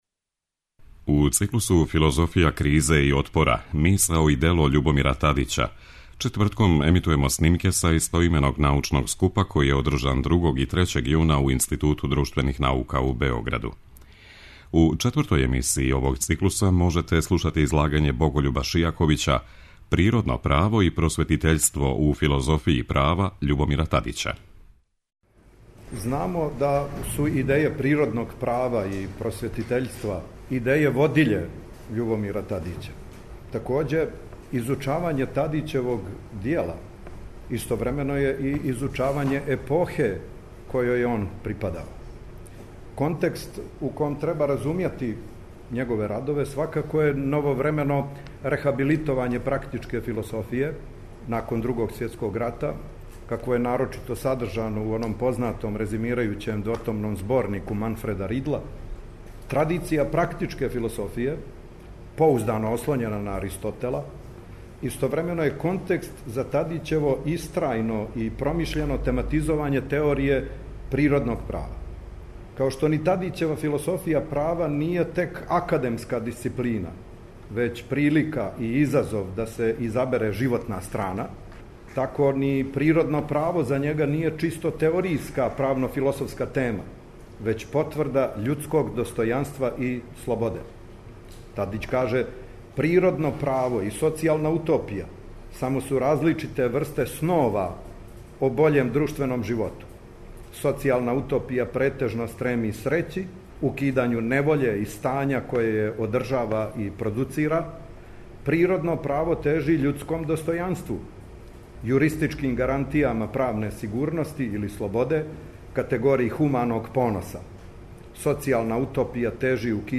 преузми : 10.61 MB Трибине и Научни скупови Autor: Редакција Преносимо излагања са научних конференција и трибина.